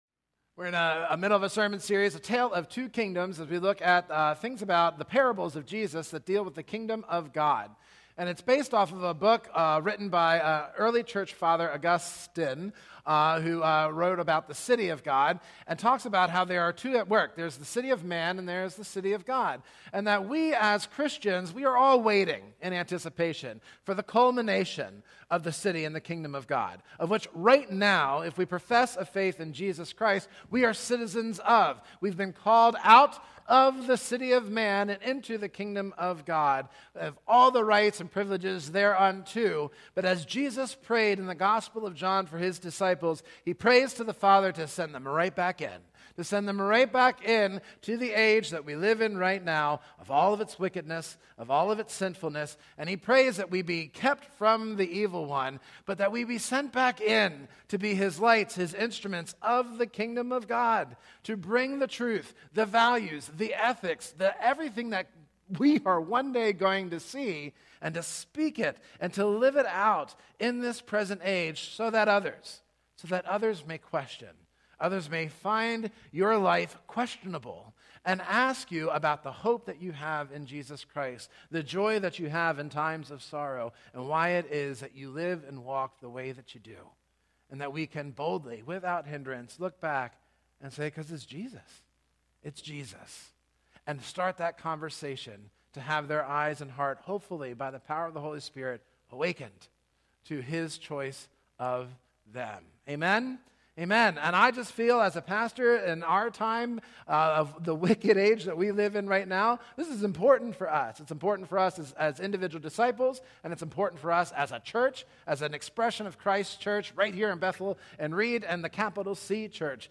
In this sermon series, we will explore Jesus’ Kingdom parables to learn what this new citizenship means for our faith, our loyalties, and our daily lives, and how Christ sends us into the world to bear witness to His Kingdom—freeing us from the idols, false promises, and misplaced hopes of the kingdom of man.